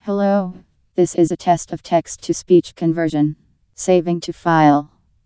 generated_speech.wav